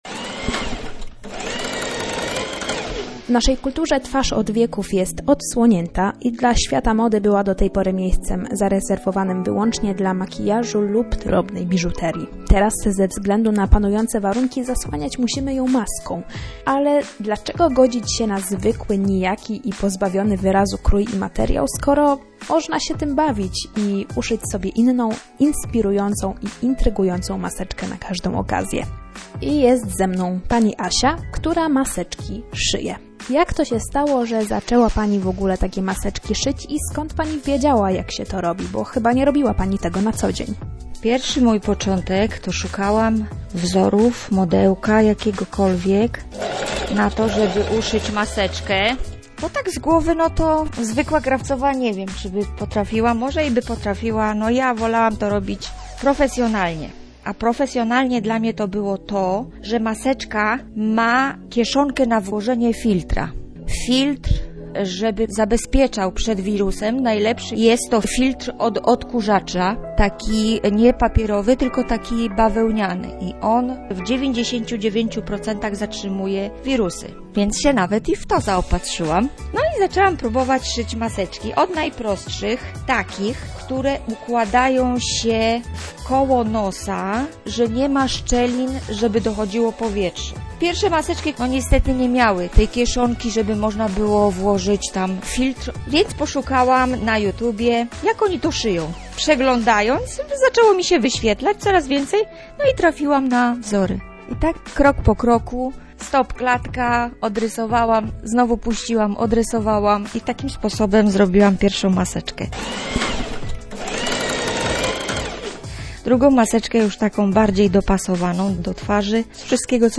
Materiał powstał oczywiście przy zachowaniu wszelkich zasad bezpieczeństwa i odpowiedniej odległości 🙂